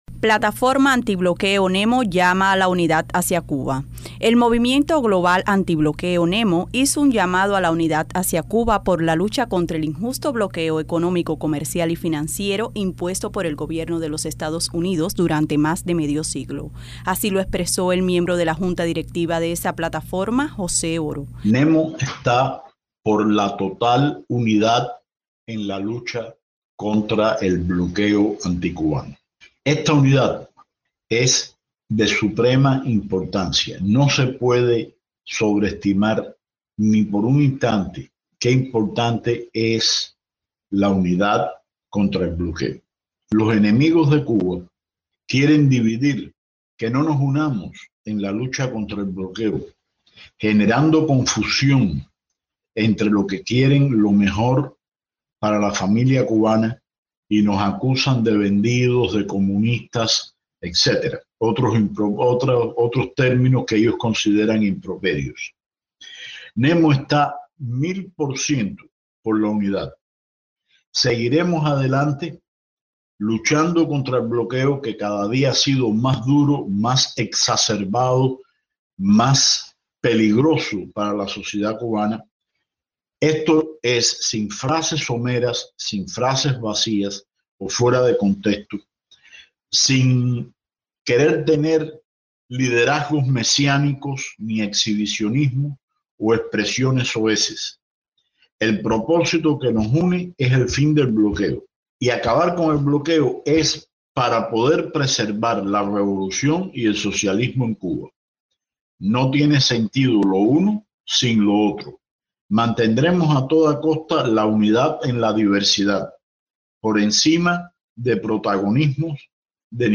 desde La Habana